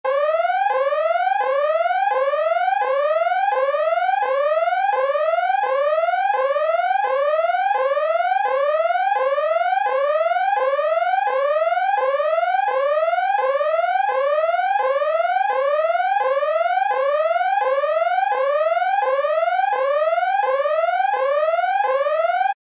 Звуки сигнала тревоги
На этой странице собрана коллекция звуков сигналов тревоги разной длительности и тональности.
Электронная тревожность